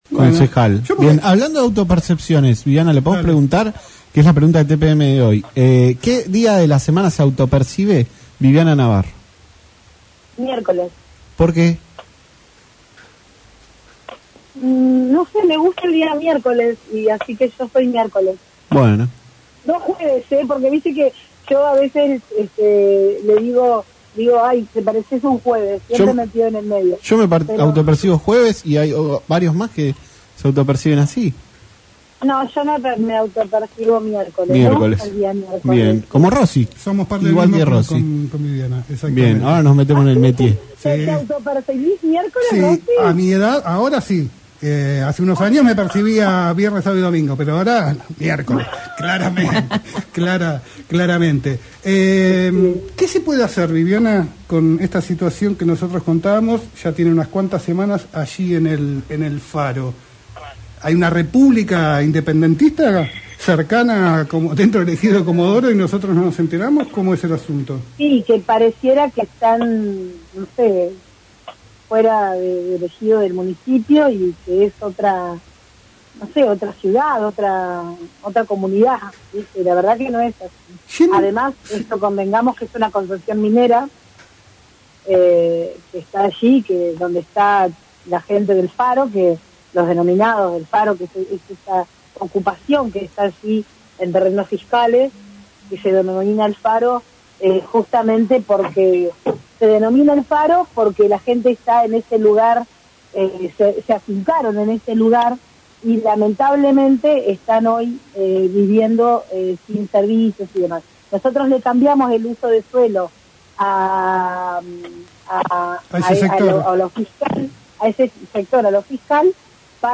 En diálogo con LaCienPuntoUno, la concejal de Comodoro Rivadavia por el Frente de Todos, Viviana Navarro, se refirió al proyecto donde se le solicita al Poder Ejecutivo Municipal que se arbitren los medios necesarios para que la empresa “Chacras del Faro S.R.L.”, cumpla con las Ordenanzas Municipales. Este pedido se hizo luego de que la empresa cercara el terreno para un barrio privado sin dejar acceso a la playa de kilómetro 8.